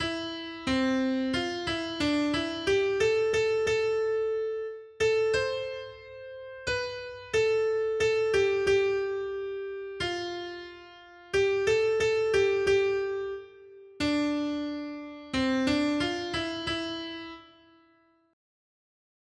Noty Štítky, zpěvníky ol608.pdf responsoriální žalm Žaltář (Olejník) 608 Skrýt akordy R: Svou starost hoď na Hospodina, a on tě zachová. 1.